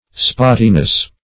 Search Result for " spottiness" : The Collaborative International Dictionary of English v.0.48: spottiness \spot"ti*ness\ (sp[o^]t"t[i^]*n[e^]s), n. The state or quality of being spotty.